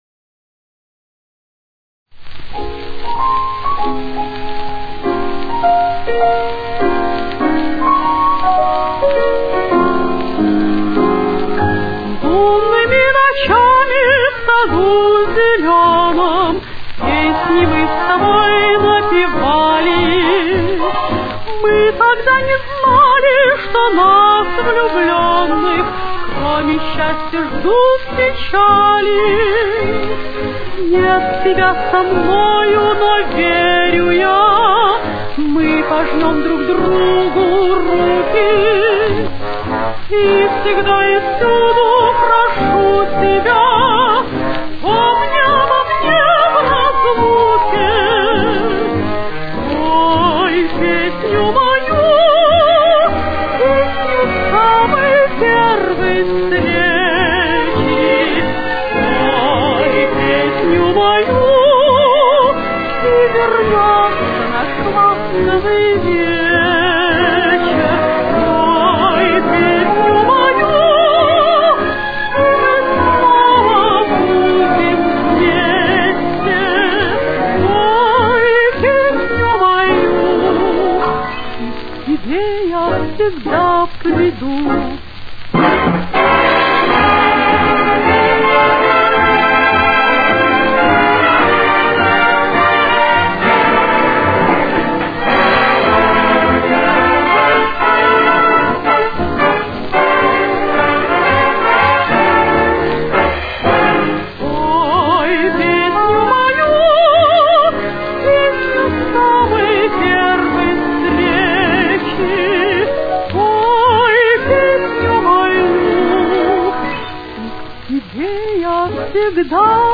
с очень низким качеством (16 – 32 кБит/с)
Темп: 105.